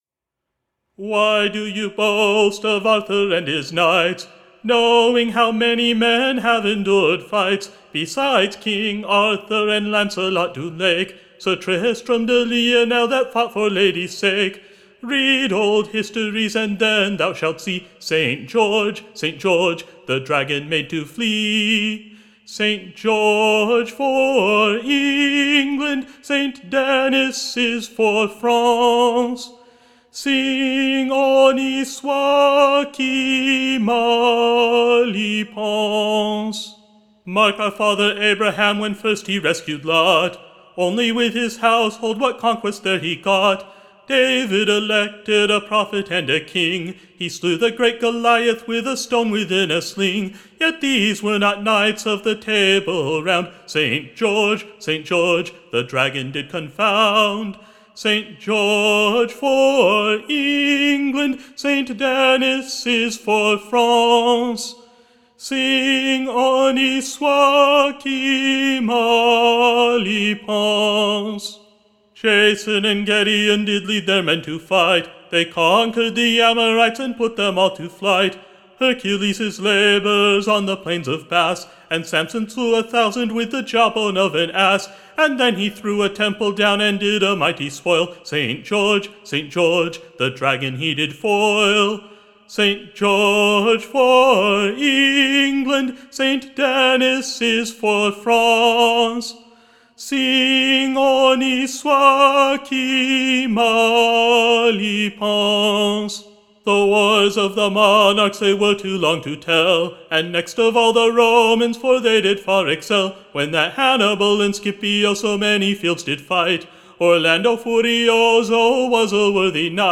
sung to “St. George for England